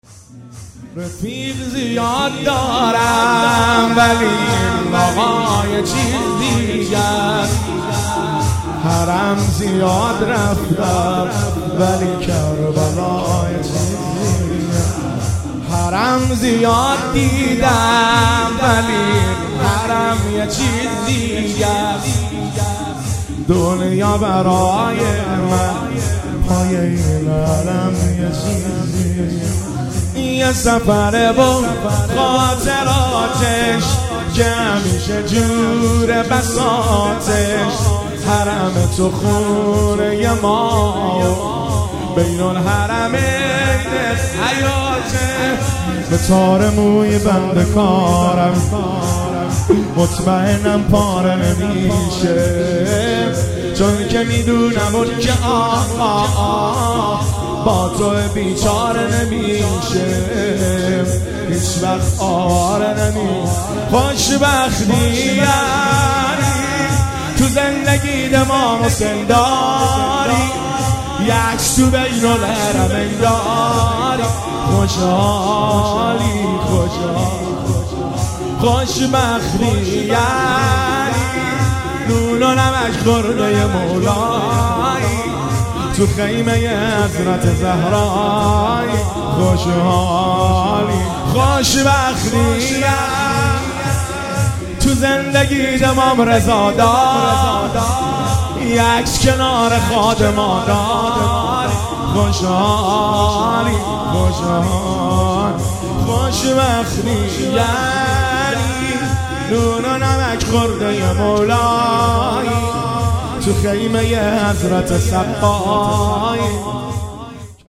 موکب الشهدا ساوجبلاغ